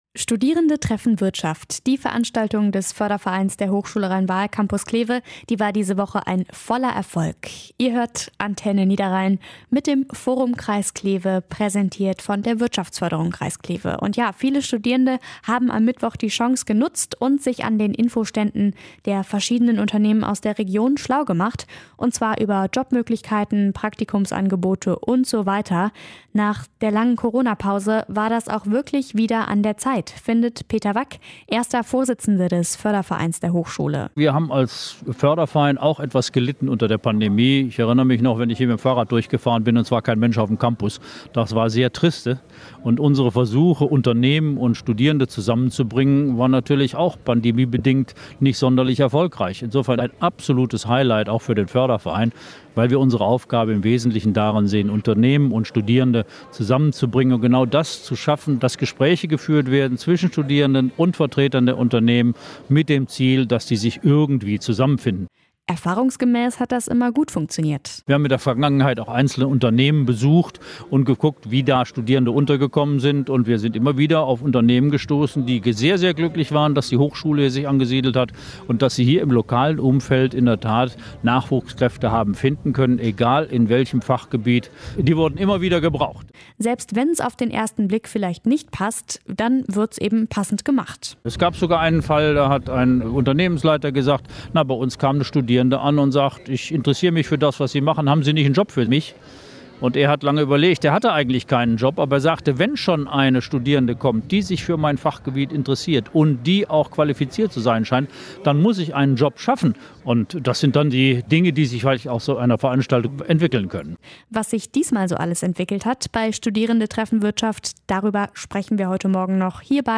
Forum Interview 2